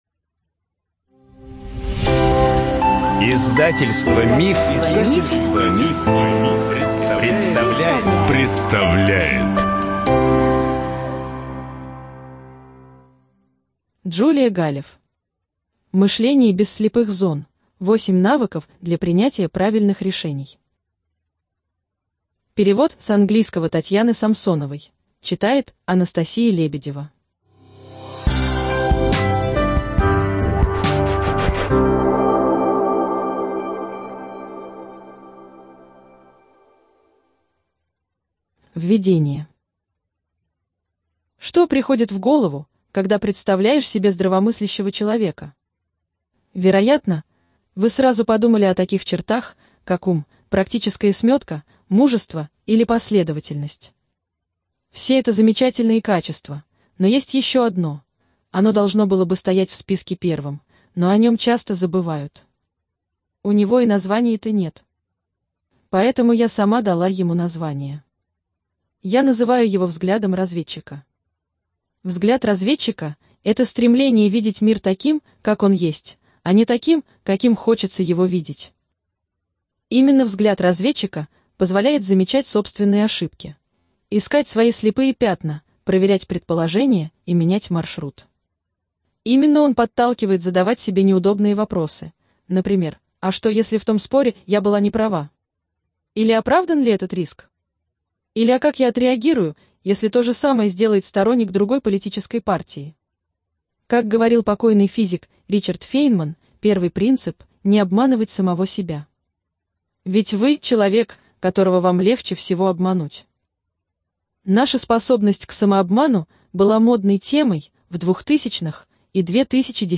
Студия звукозаписиМанн, Иванов и Фербер (МИФ)